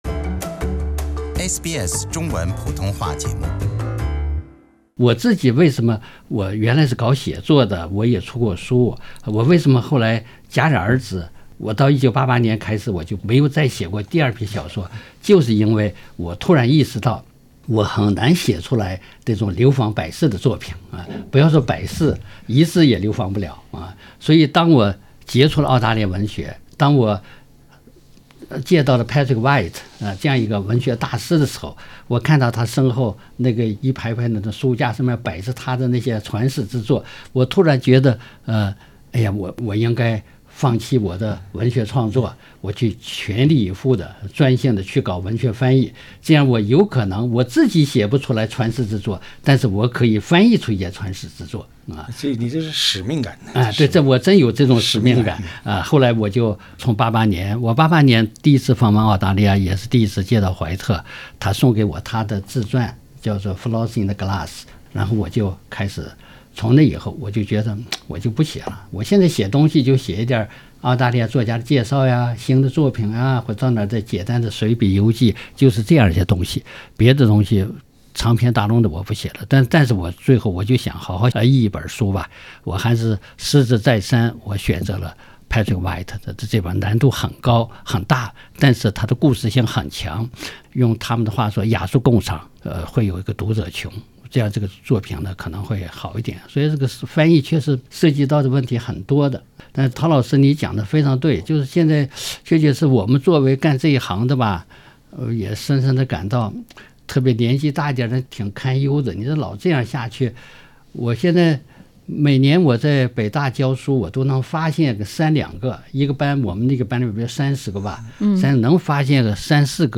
歡迎收聽SBS 文化時評欄目《文化苦丁茶》，本期話題是： “飛鳥”過後，鄭振鐸變郭敬明？（全集)